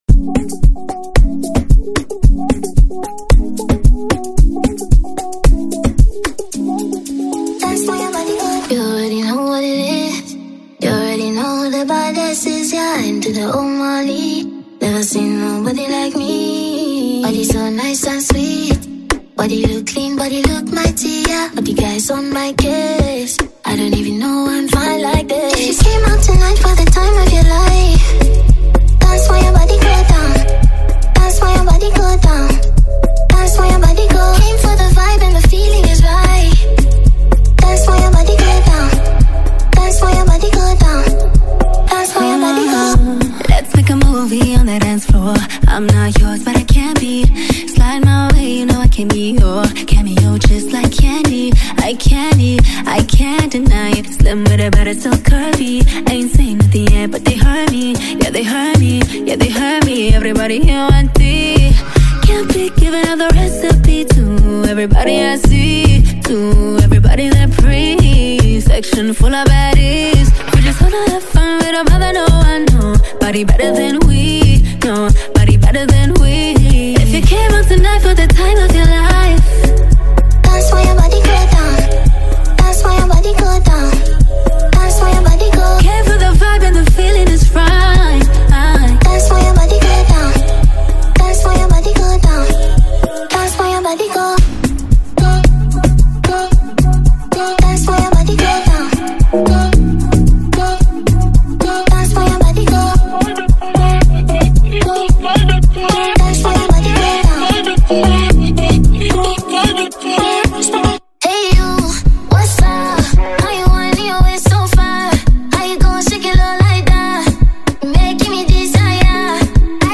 Genre: Afrobeat
and enjoy the vibrant sounds of Ghanaian dancehall music.